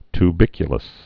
(t-bĭkyə-ləs, ty-)